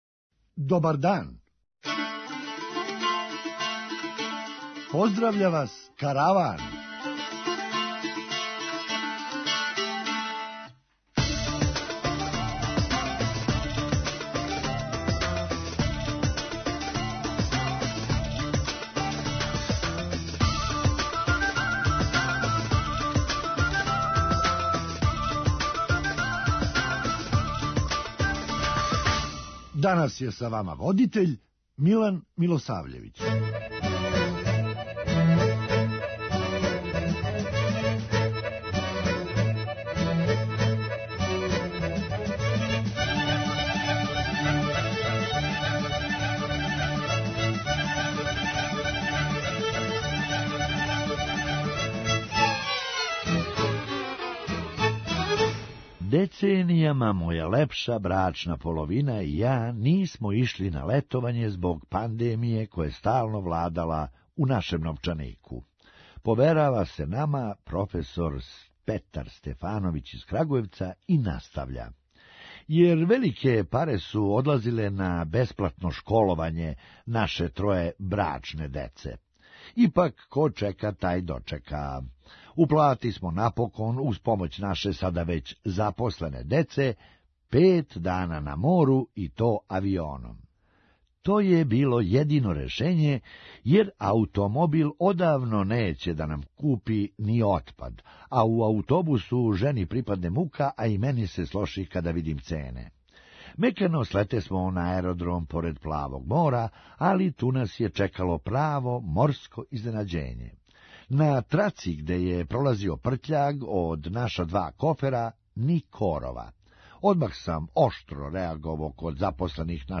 Хумористичка емисија
Када мало боље погледамо, изгледа да за недељу закони не важе . преузми : 9.11 MB Караван Autor: Забавна редакција Радио Бeограда 1 Караван се креће ка својој дестинацији већ више од 50 година, увек добро натоварен актуелним хумором и изворним народним песмама.